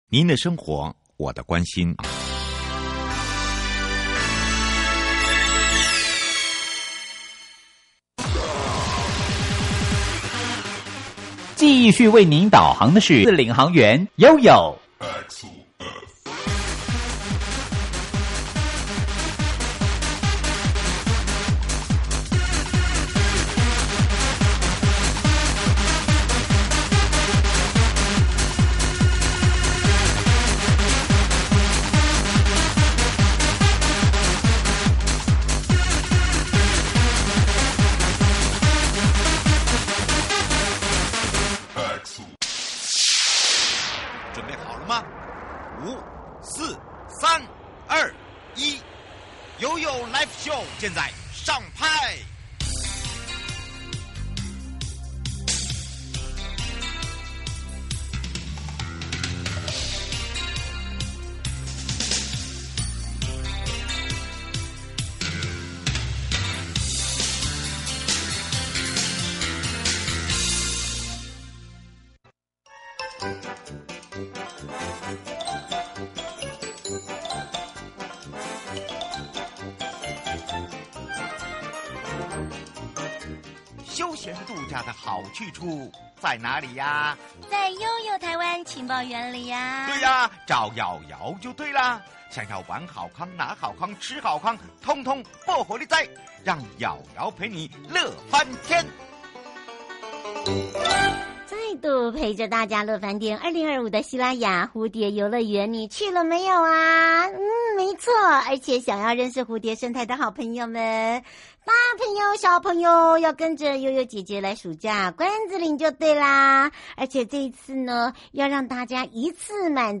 受訪者： 1.西拉雅管理處許主龍處長 2.花東縱谷管理處許宗民處長